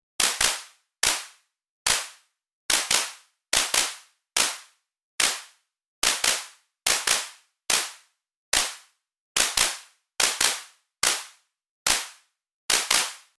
Been trying but the best I can do is still not quite there: